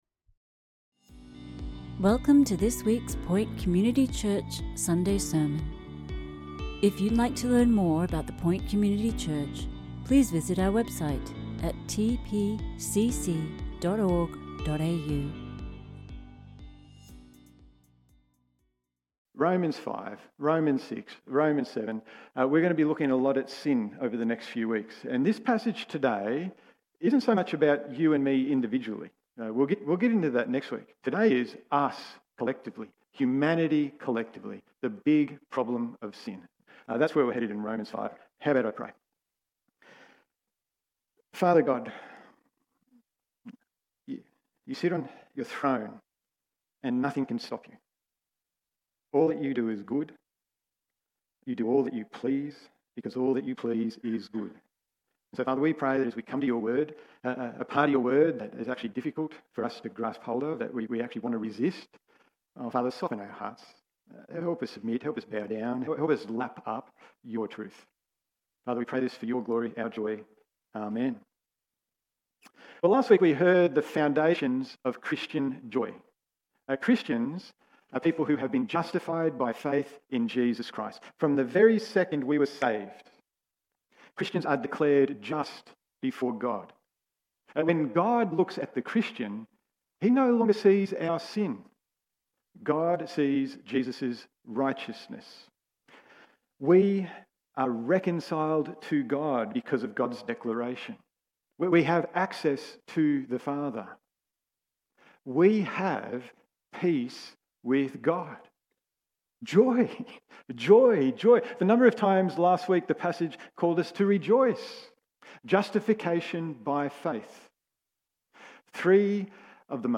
Sermons | The Point Community Church